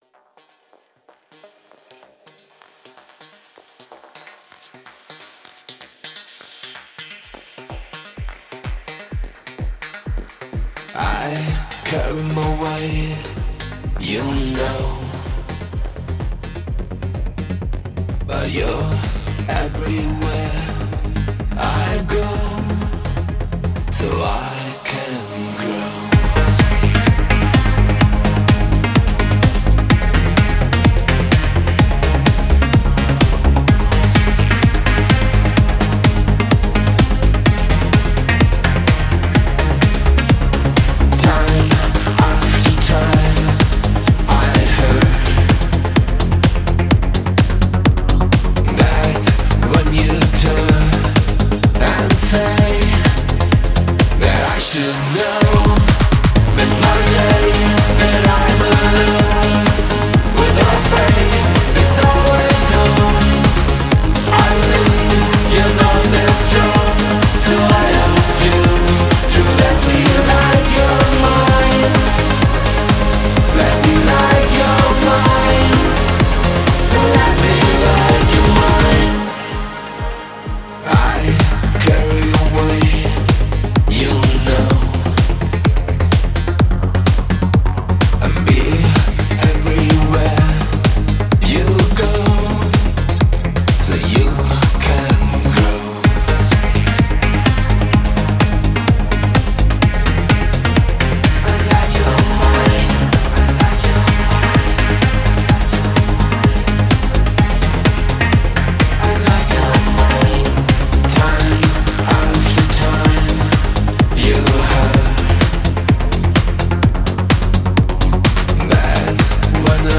EBM and Industrial net radio show